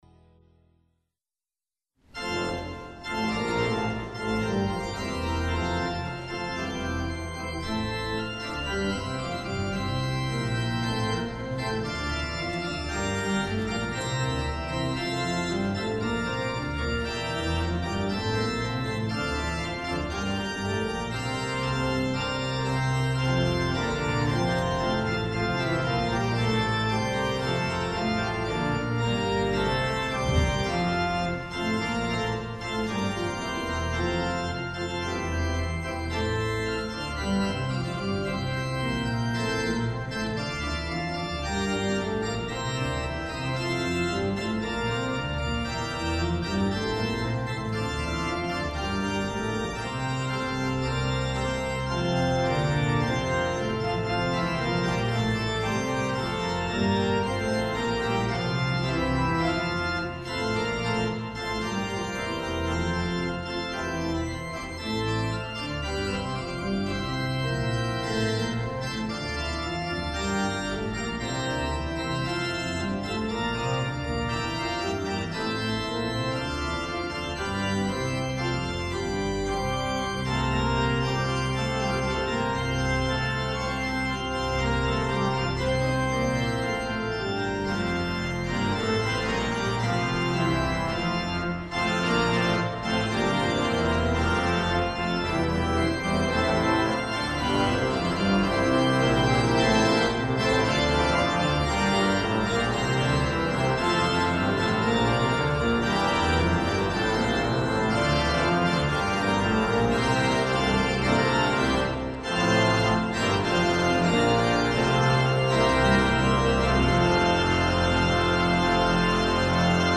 Hear the Bible Study from St. Paul's Lutheran Church in Des Peres, MO, from February 23, 2025.
Join the pastors and people of St. Paul’s Lutheran Church in Des Peres, MO, for weekly Bible study on Sunday mornings.